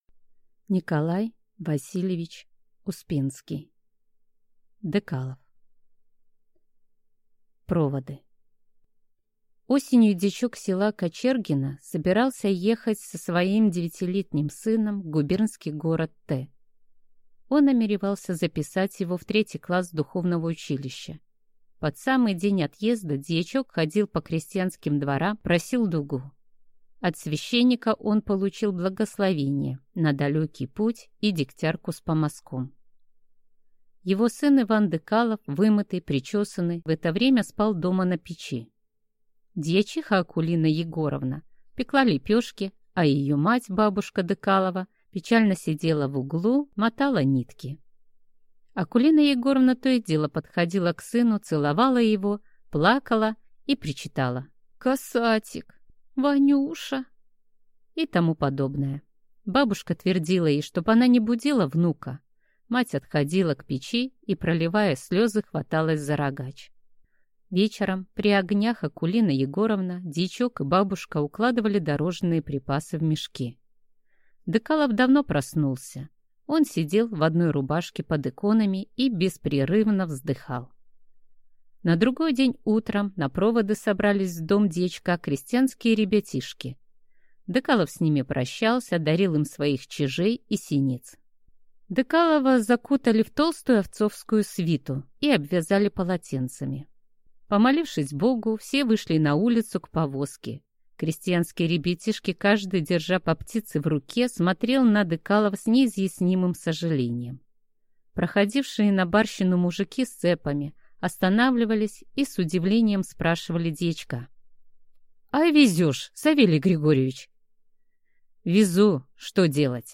Аудиокнига Декалов | Библиотека аудиокниг